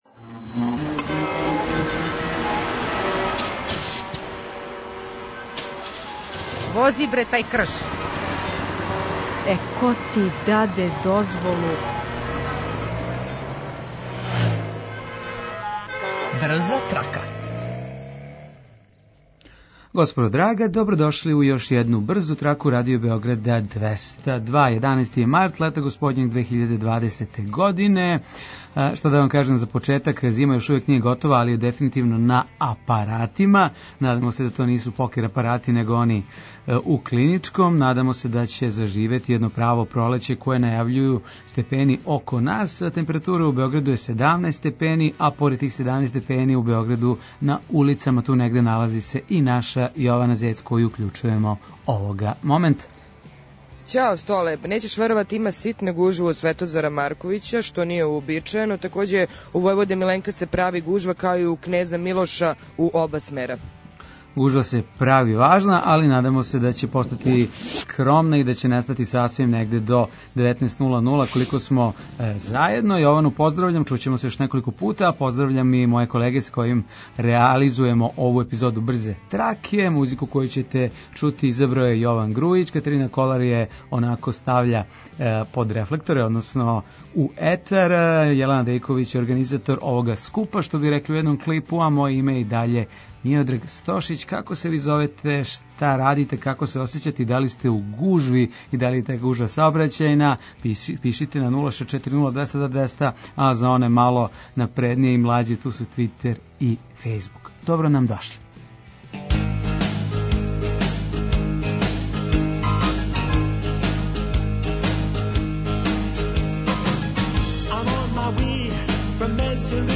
Репортер